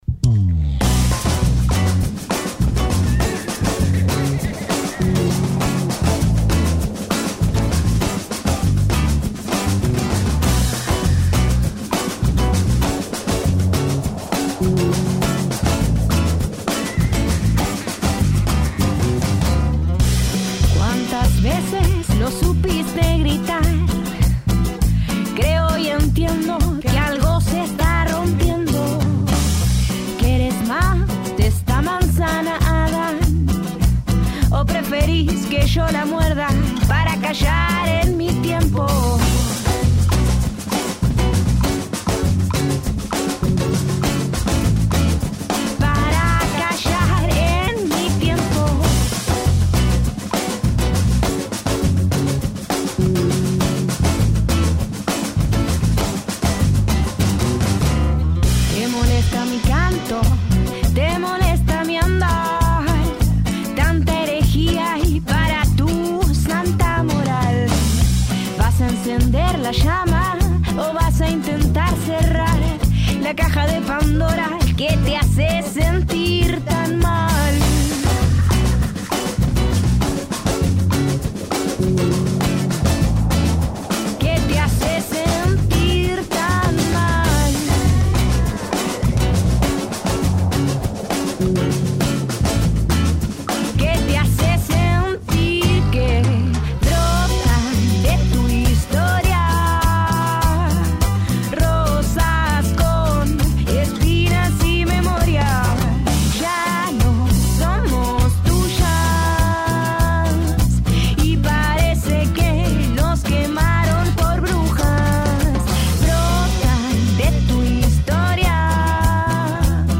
entrevista y acústico